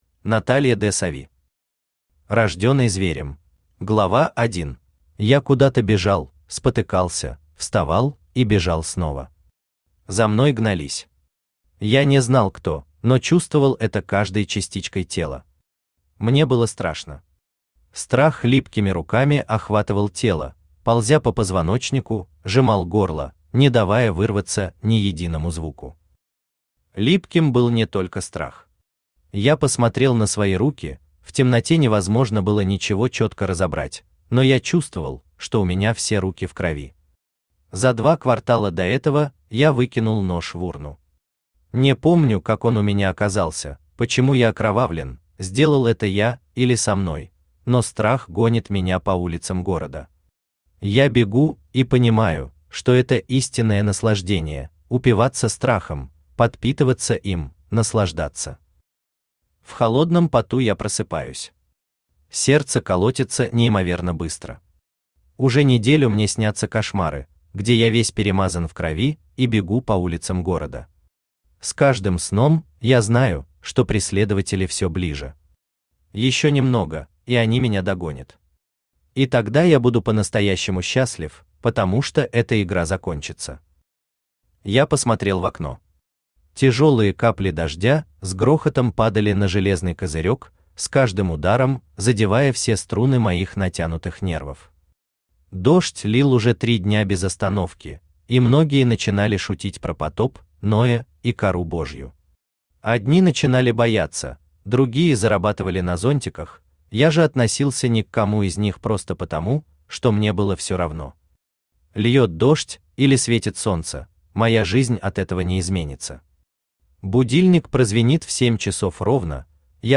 Аудиокнига Рожденный зверем | Библиотека аудиокниг
Aудиокнига Рожденный зверем Автор Наталья ДеСави Читает аудиокнигу Авточтец ЛитРес.